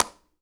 Clap9.wav